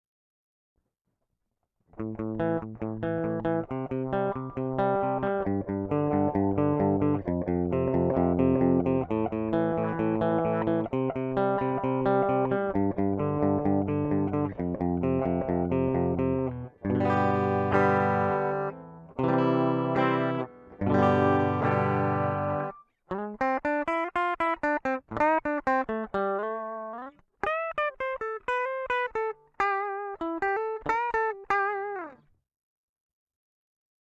• Przetwornik do 7-mio strunowej gitary elektrycznej
• Agresywne brzmienie , z ogromnym uderzeniem, zoptymalizowane do metalu
Brzmienie jest dosyć jasne z dużą ilością średnich tonów oraz odpowiednią ilością basu.
Ogólnie brzmienie jest bogate i dosyć ostre, a także nie brakuje w nim również środka, który dodaje mu przyjemne ciepło.